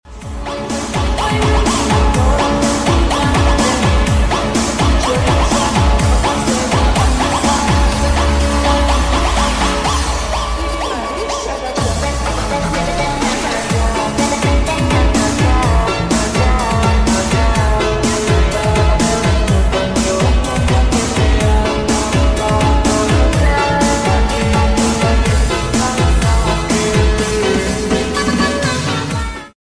Aircraft traffic in Denpasar ✈📸